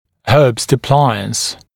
[hɜːbst ə’plaɪəns][хё:бст э’плайэнс]аппарат Гербста